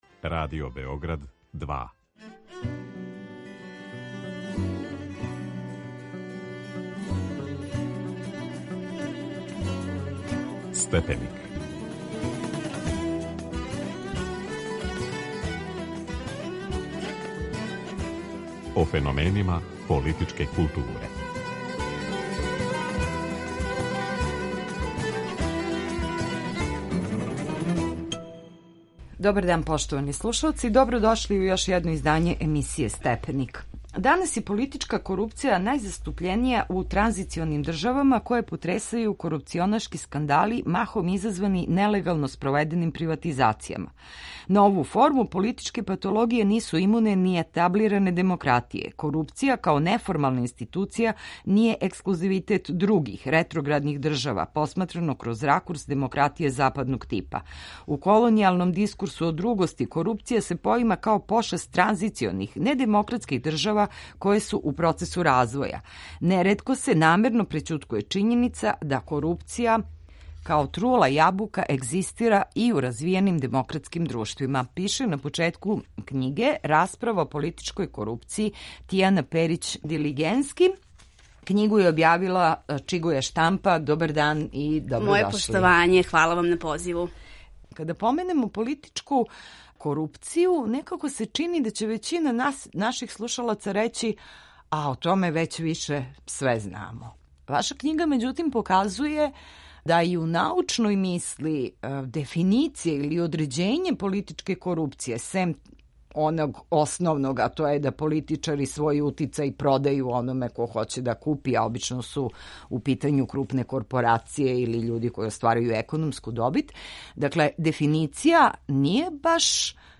Гошћа